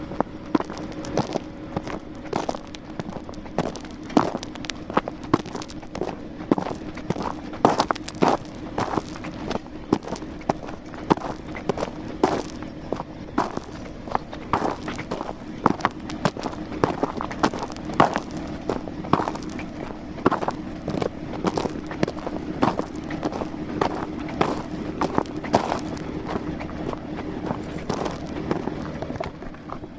Station 3: Flucht Geräusch: Schritte auf steinigem Weg. Woher � wohin � warum � wie lange noch?